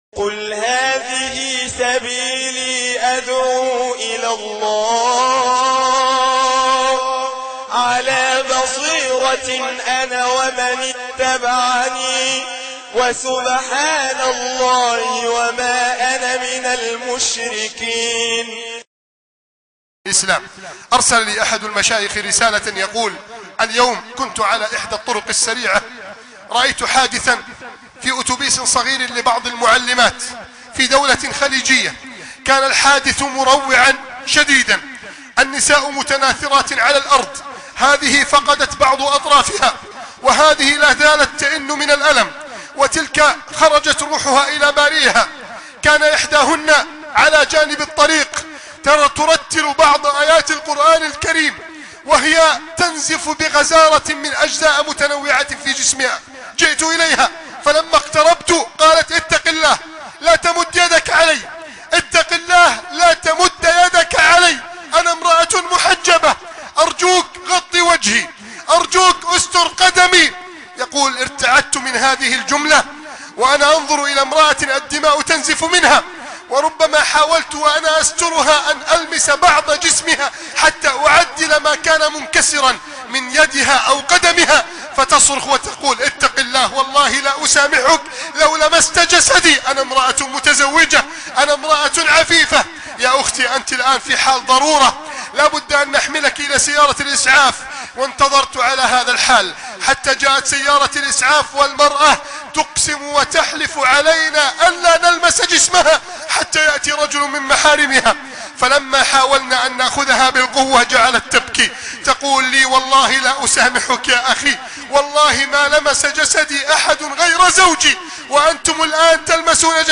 عنوان المادة حياء بحياء " قصة مؤثرة عن حياء إمرأة مسلمة " ( مسجد التابعين - بنها )